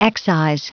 Prononciation du mot excise en anglais (fichier audio)
Prononciation du mot : excise